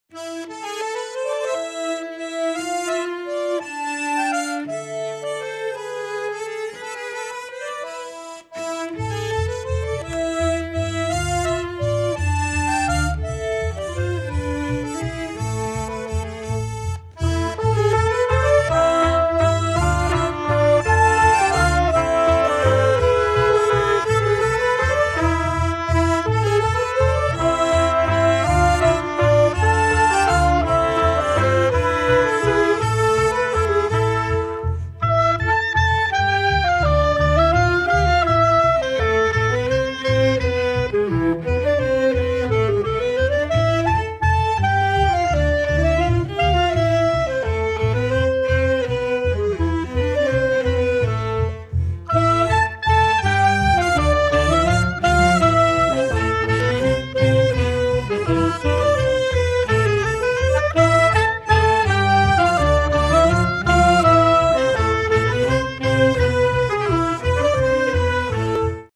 (Traditional Music)